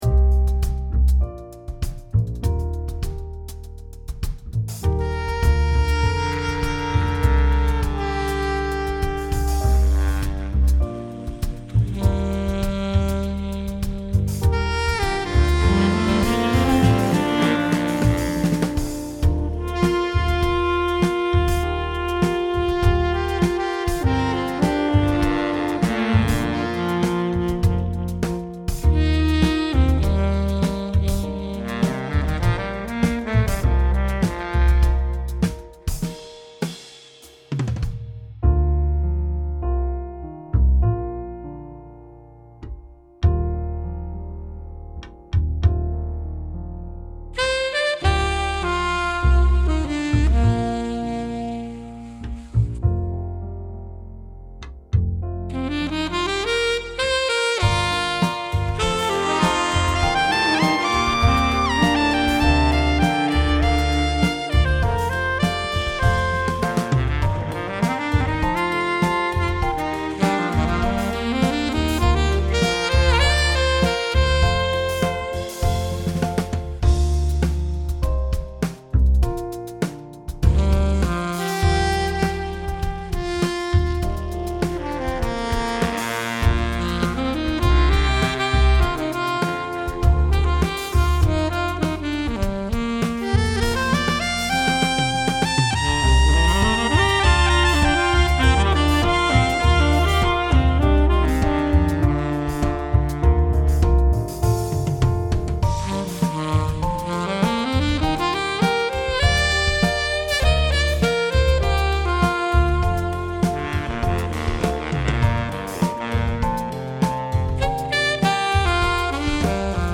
Vier Stimmenteilen sich einen Atem,manchmal allein,manchmal zugleich.
Darunterder Puls der Drums,der lange Gang des Basses,Gedanken im Klavier.